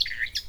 warbler.004.wav